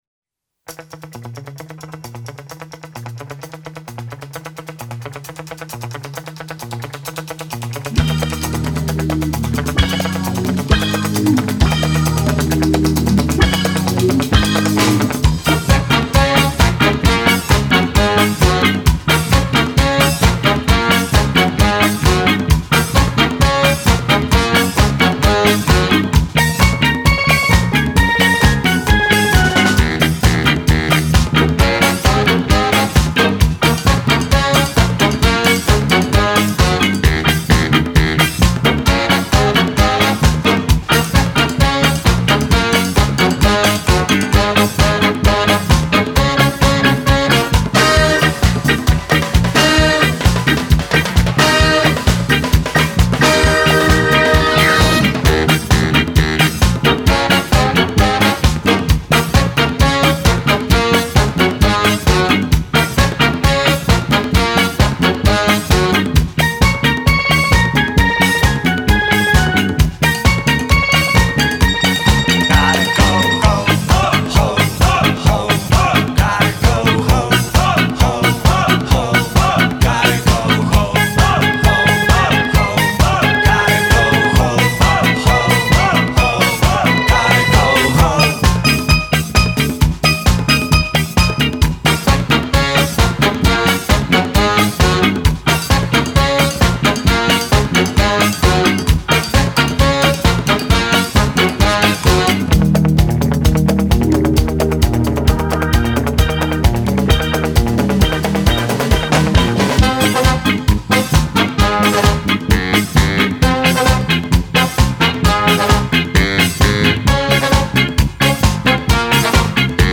Стили СКА и ска-джаз...